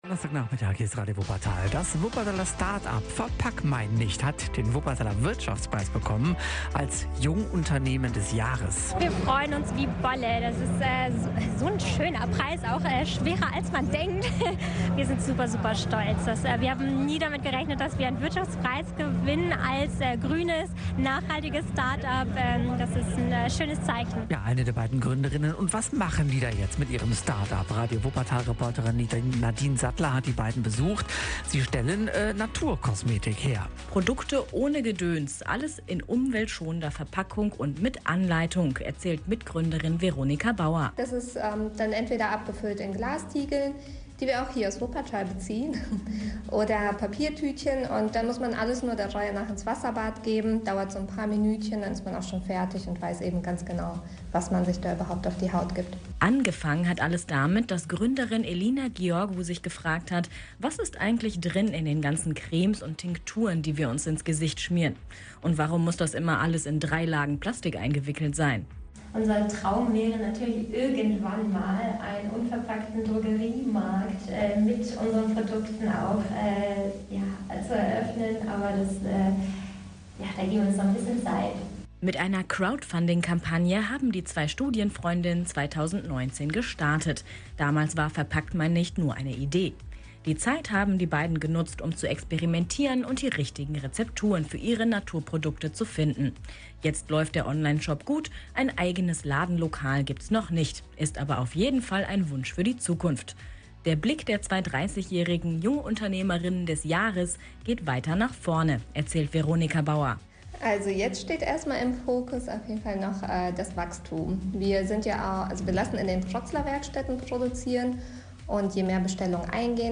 Am 27. Oktober 2021 wurden in der Glashalle der Sparkasse die Wuppertaler Wirtschaftspreise 2021 verliehen.
wuppertaler_wirtschaftspreis_mitschnitt_verpackmeinnicht_jungunternehmen-ii.mp3